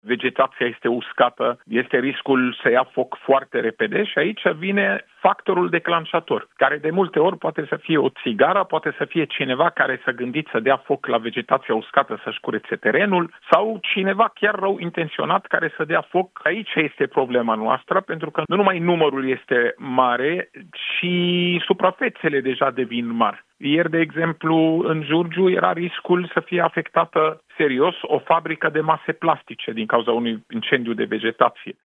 Șeful DSU, Raed Arafat: „Vegetația este uscată, există riscul să ia foc foarte repede”